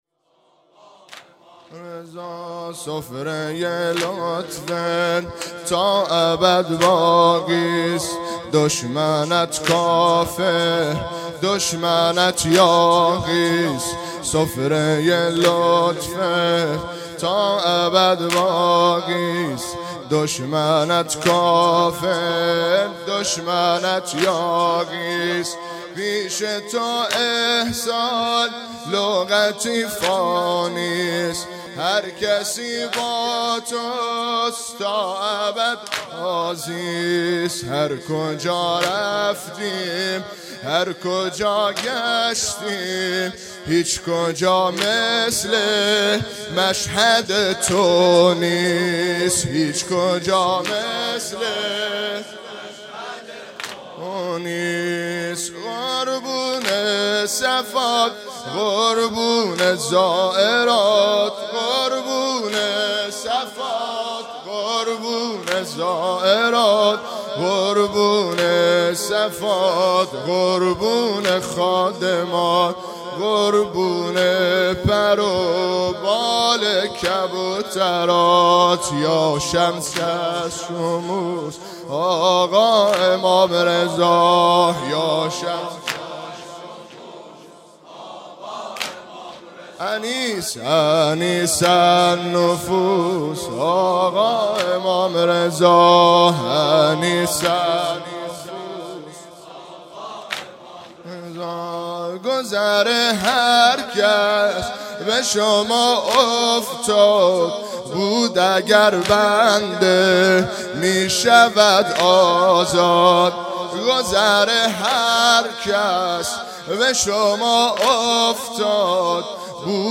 مجموعه صوتی عزاداری شام شهادت امام رضا (علیه السلام)